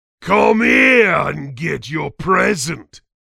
Extracted with BSPZip from the various official vsh .bsp files.
Licensing This is an audio clip from the game Team Fortress 2 .
Category:Saxton Hale audio responses You cannot overwrite this file.
Saxton_Hale_Maul_saxton_punch_ready_01.mp3